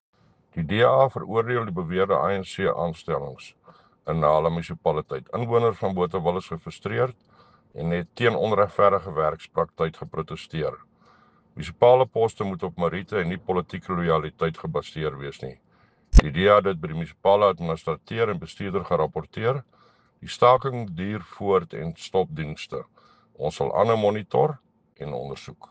Afrikaans soundbites by Cllr Thinus Barnard and